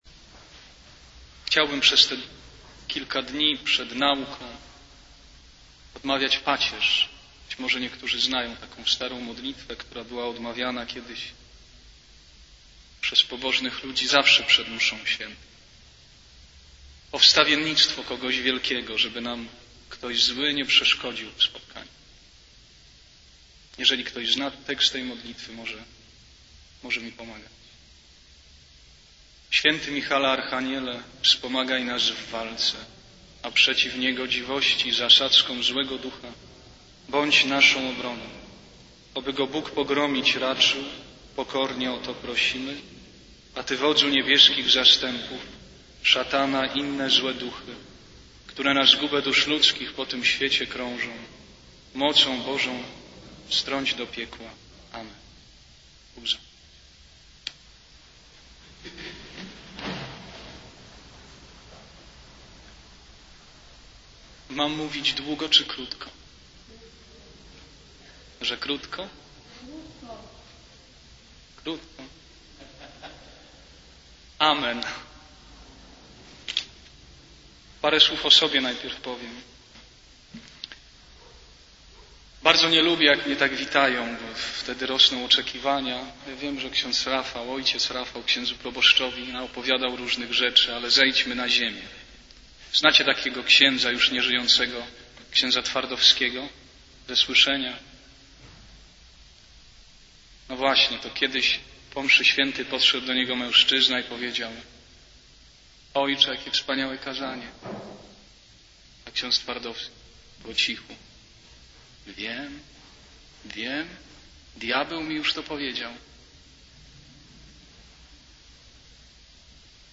Rekolekcje wielkopostne
I_Rekolekcje_dla_mlodziezy_-_Slowo_Boze.mp3